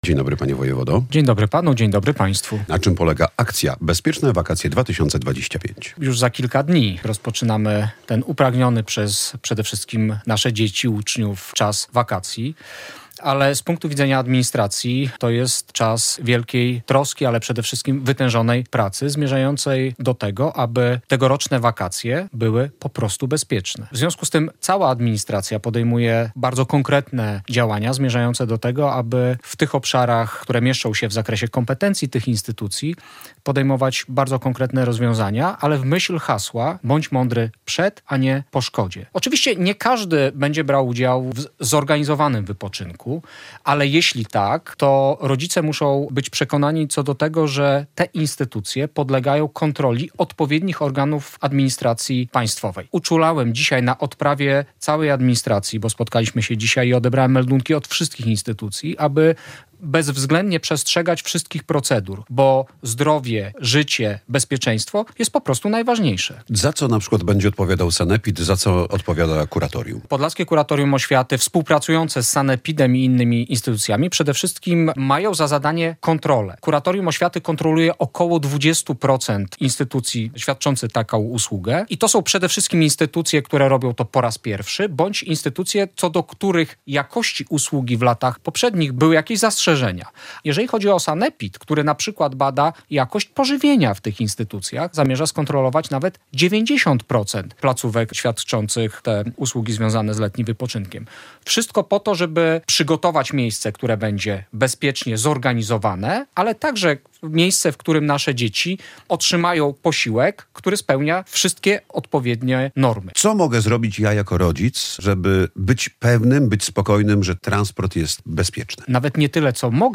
Z wojewodą podlaskim Jackiem Brzozowskim rozmawia